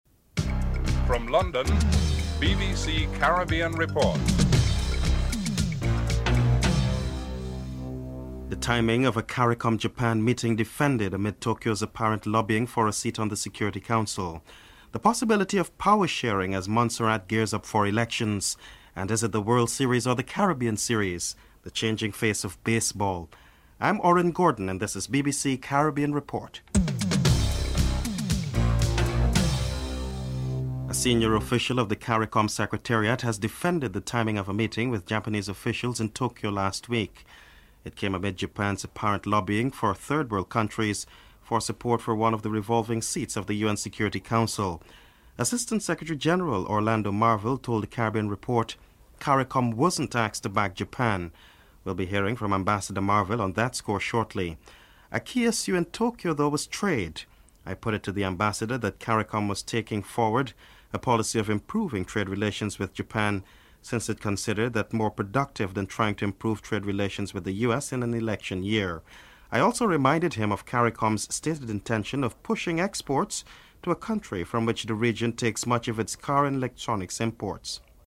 1. Headlines (00:00-00:30)
2. The timing of a Caricom Japan meeting is defended amid Tokyo's apparent lobbying for a seat in the Security Council. Caricom's Assistant Secretary General Orlando Marville is interviewed (00:31-03:58)